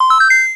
collectPack.mp3